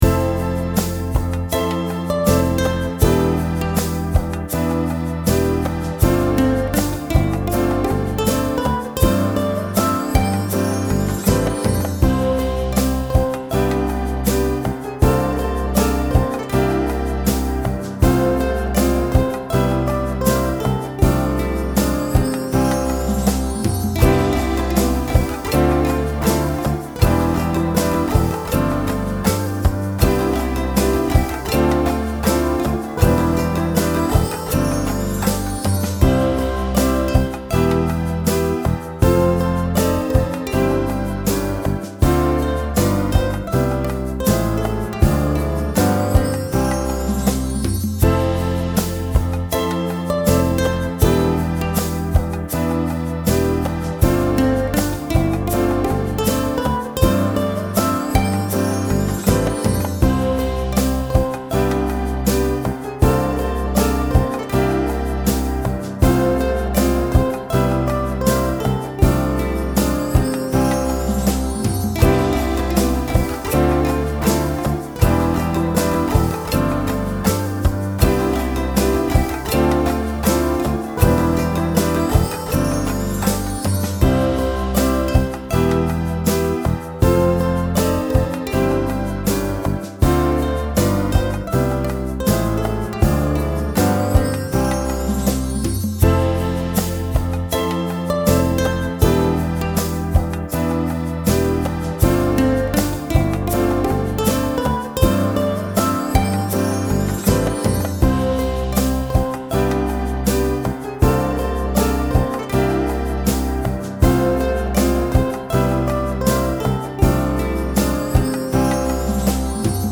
hier hinterlegt habe, verlief das Melodiespiel flüssig und verlangte nach einer Fortsetzung, die wir in „Twinkle, twinkle, little Star“ fanden (ein Notenblatt liegt hier und das Begleit-Playback an